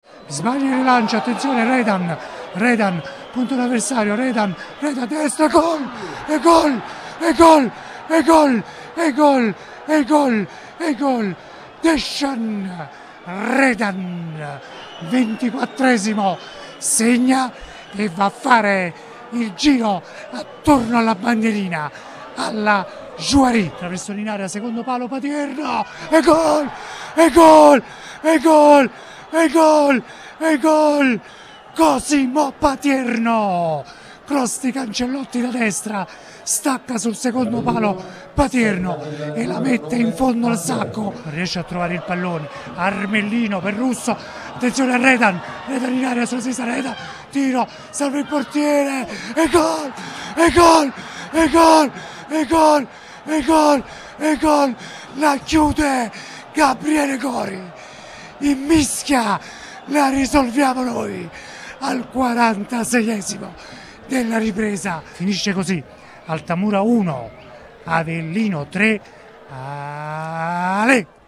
PODCAST | T. ALTAMURA AVELLINO 1-3: RIASCOLTA L’ESULTANZA